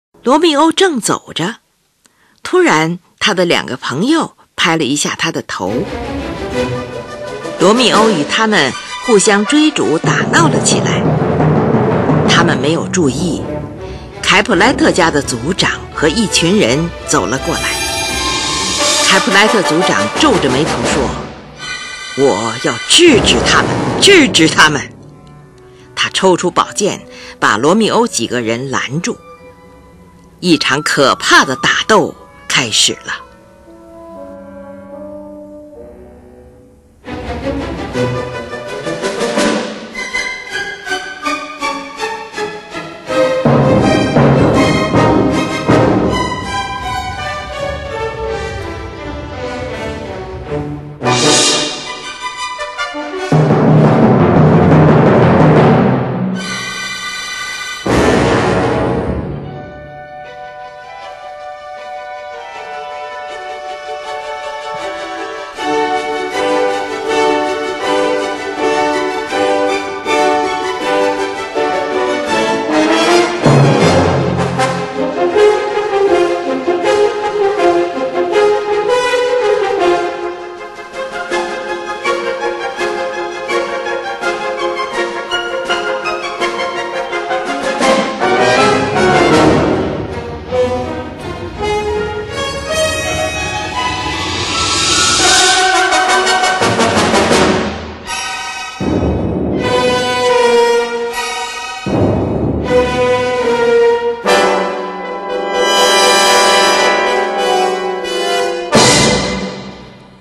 三幕芭蕾舞剧
美妙动人、丰富多彩的旋律与和声，复杂多样化的节奏以及雄魄壮阔、精致华丽的配器，把舞剧音乐提高到一个崭新的时代高度。